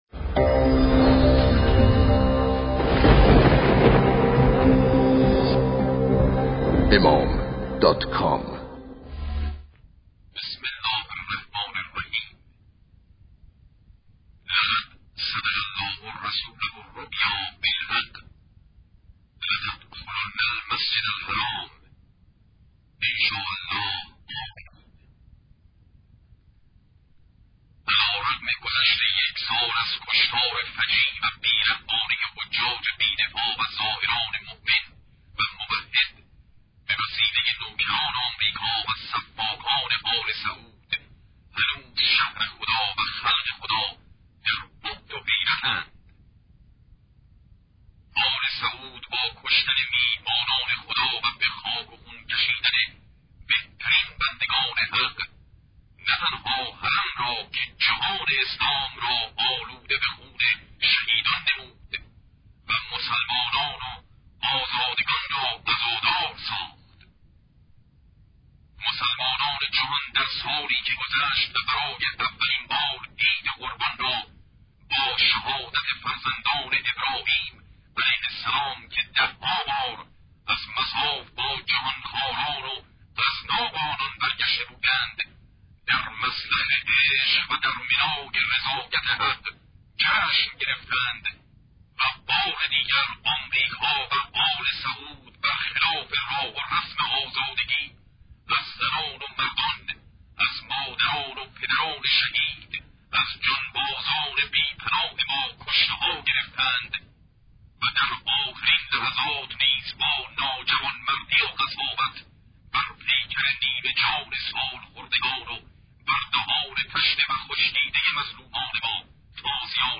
قرائت پیام قطعنامه